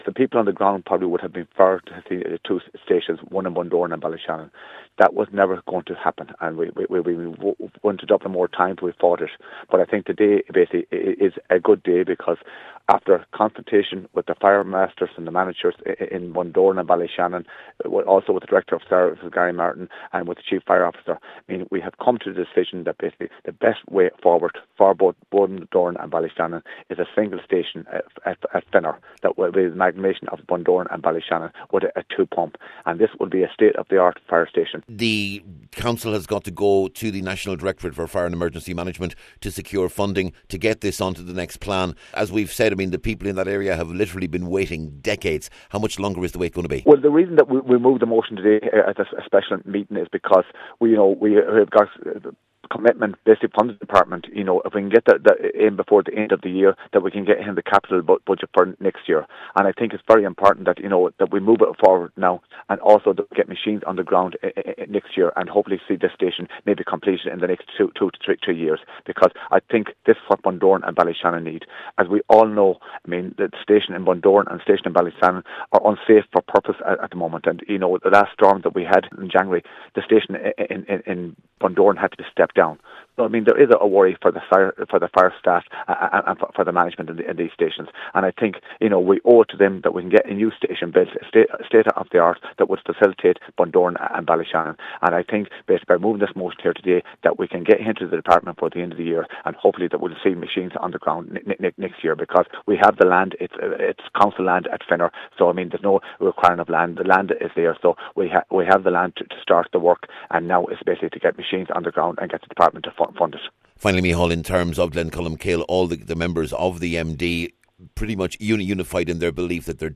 MD Cathaoirleach Clle Michael Naughton says today is a special day for South Donegal……..